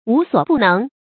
無所不能 注音： ㄨˊ ㄙㄨㄛˇ ㄅㄨˋ ㄣㄥˊ 讀音讀法： 意思解釋： 沒有什么不能做的。